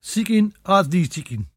[seek-een: aat thee seek-een]